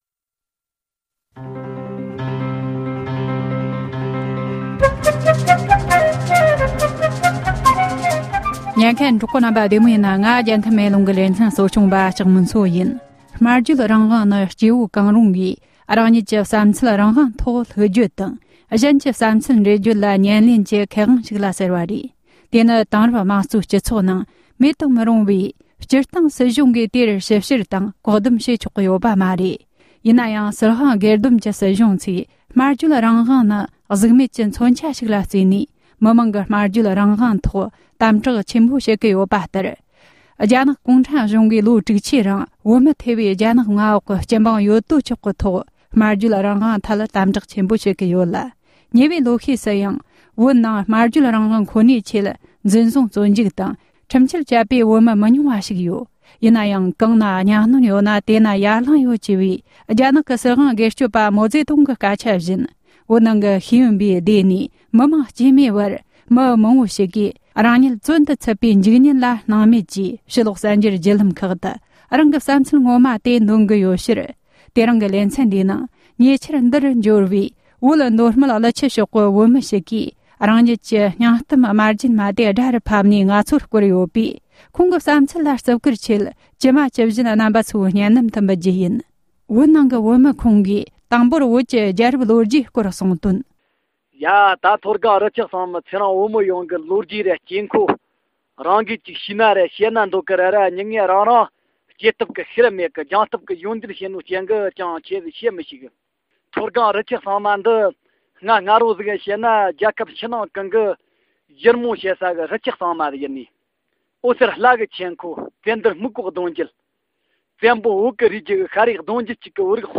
བོད་མདོ་སྨད་ཀླུ་ཆུ་ཕྱོགས་བོད་མི་ཞིག་གིས་བོད་ནང་གི་གནས་སྟངས་བརྗོད་པའི་སྒྲ་ཞིག་འབྱོར་བའི་སྙིང་བསྡུས།
སྒྲ་ལྡན་གསར་འགྱུར།